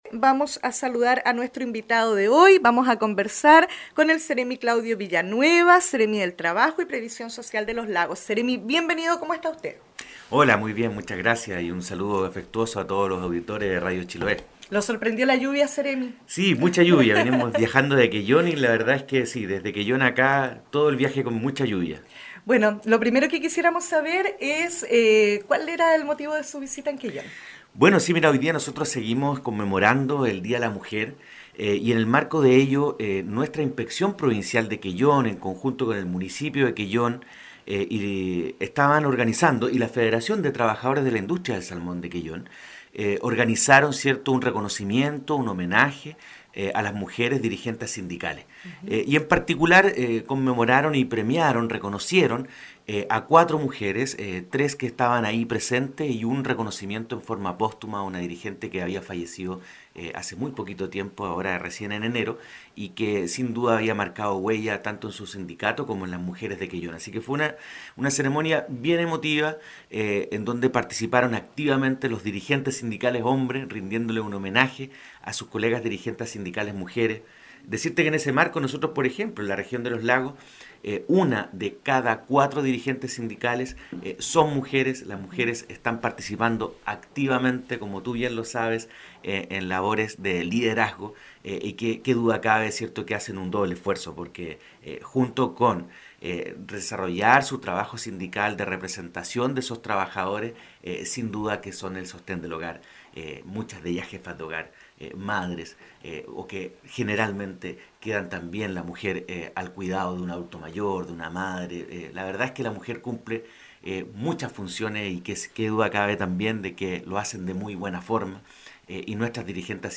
Seremi del Trabajo destacó avances del Gobierno en materia laboral en entrevista en Radio Chiloé (audio) – Canal Regional – Región de Los Lagos
Tras asistir a una ceremonia de reconocimiento de mujeres dirigentes sindicales en Quellón, junto a la Federación de Trabajadores de Quellón, representantes del municipio de Quellón y funcionarios de la Inspección del Trabajo de esa ciudad, el Seremi Claudio Villanueva sostuvo una interesante entrevista en los estudios de Radio Chiloé en Castro.